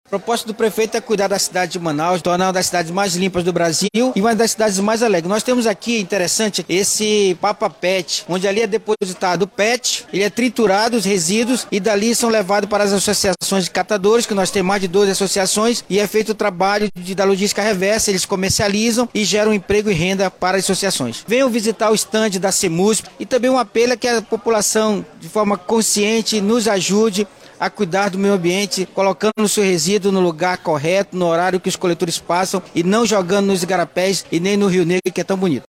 Esse ano, a Prefeitura de Manaus, por meio da Secretaria Municipal de Limpeza Urbana (Semulsp), participa da 44ª Exposição Agropecuária do Amazonas (Expoagro), com exposição voltada para Educação Ambiental, equipes de conscientização ambiental da Semulsp estarão no local, durante todo o evento, para orientar os visitantes sobre o descarte correto dos resíduos, como explica o secretário da pasta, Altervi Moreira.